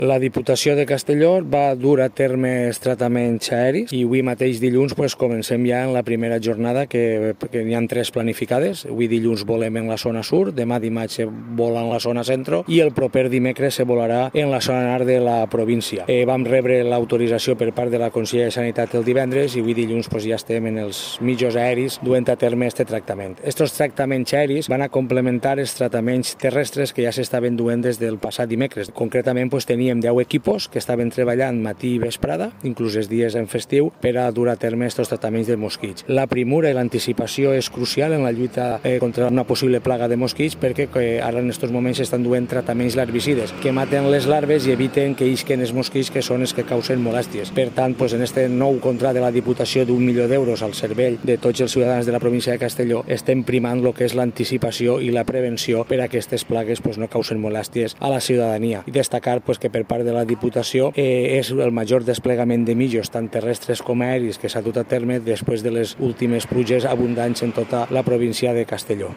Diputado-Jose-Maria-Andres-tratamiento-aereo-mosquitos.mp3